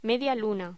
Locución: Media luna
voz